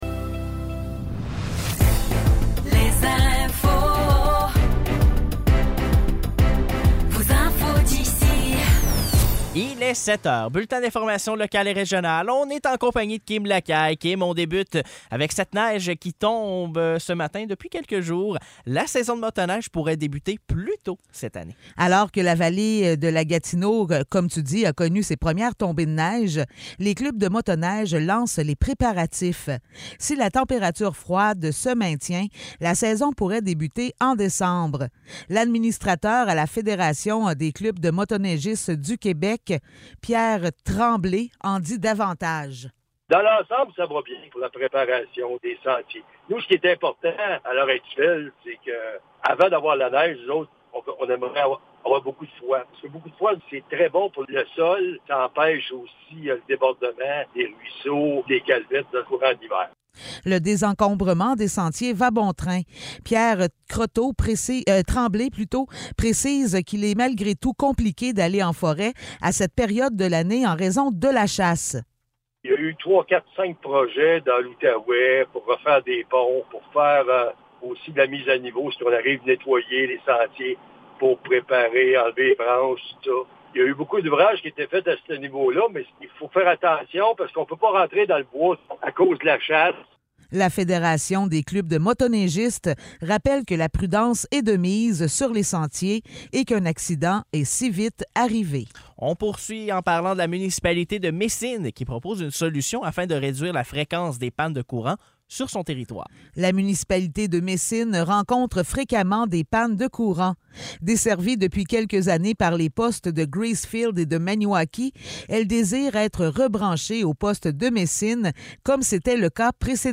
Nouvelles locales - 1er novembre 2023 - 7 h